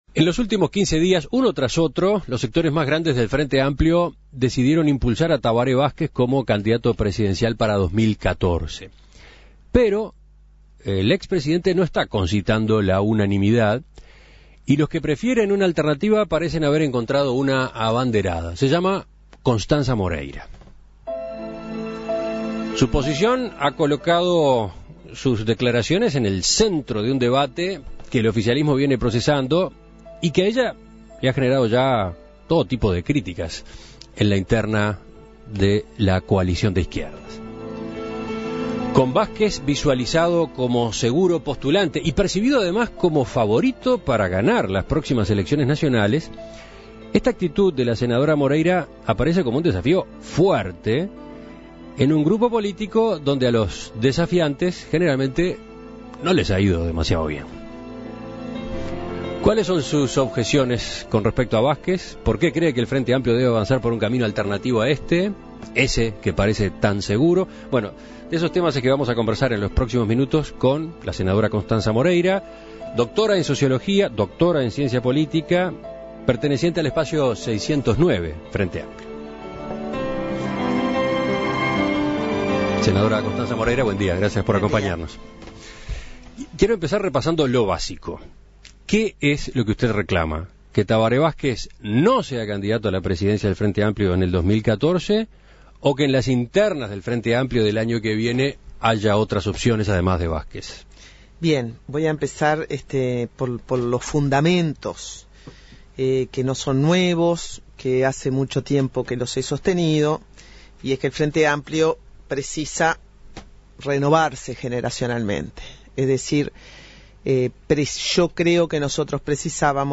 Escuche la entrevista a Constanza Moreira